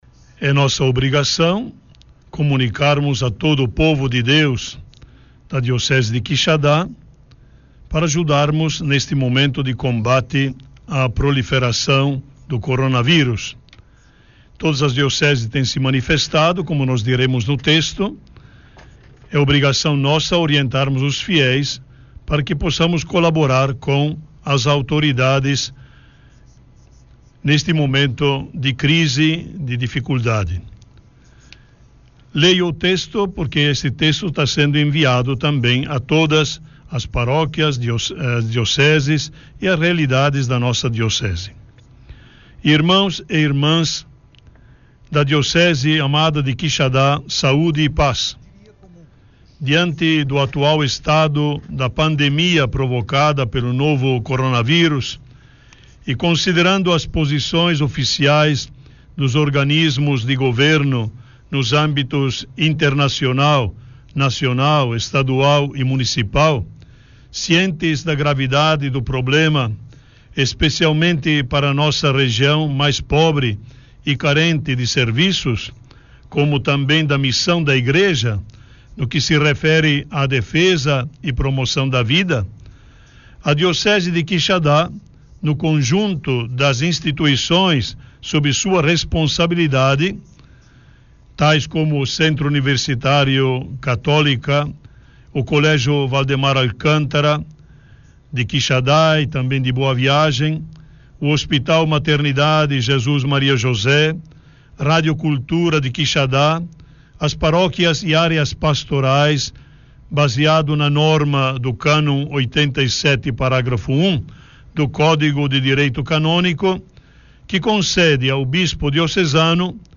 Confira: Confira o pronunciamento do bispo: Bispo diocesano Dom Ângelo Pignoli Repórter Ceará